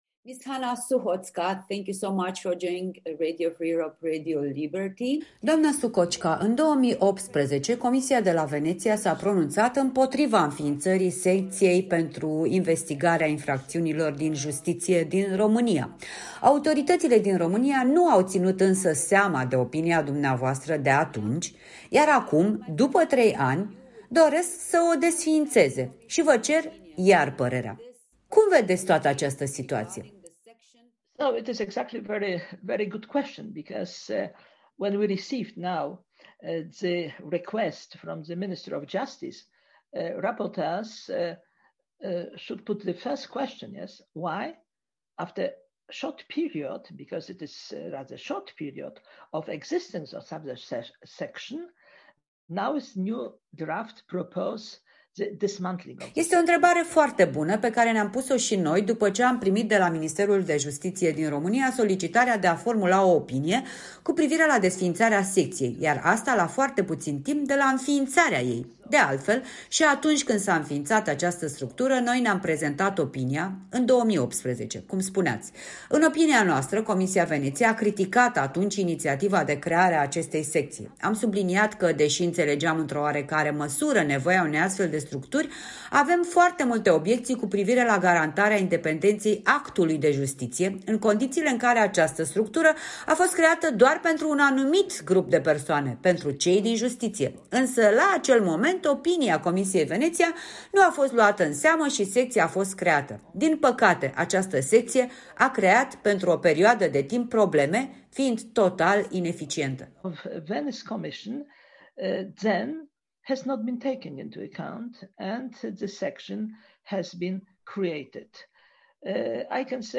„Cu trei ani în urmă, Comisia de la Veneția a criticat înființarea SIIJ nu într-unul, ci în două avize adoptate în 2018 și 2019”, arată într-un interviu acordat în exclusivitate Europei Libere Hanna Suchocka, președinte onorific al Comisiei de la Veneția.